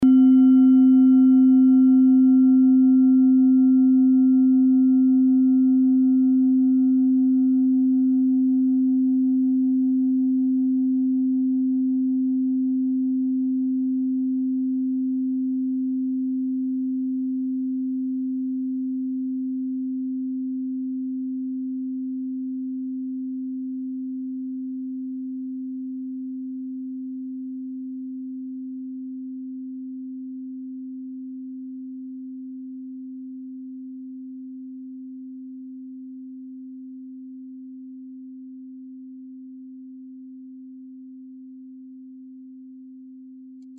Klangschale Nr.1 Bengalen
Planetentonschale: Meisterton
Diese Klangschale ist eine Handarbeit aus Bengalen. Sie ist neu und wurde gezielt nach altem 7-Metalle-Rezept in Handarbeit gezogen und gehämmert.
Hörprobe der Klangschale
Filzklöppel oder Gummikernschlegel
Klangschale-Gewicht: 790g
Klangschale-Durchmesser: 16,2cm